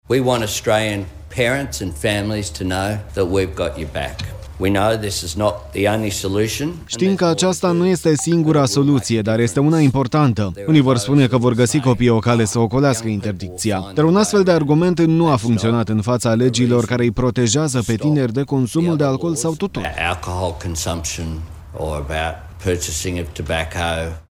Prim-ministrul Australiei, Anthony Albanese: „Sțim că aceasta nu este singura soluție, dar este importantă”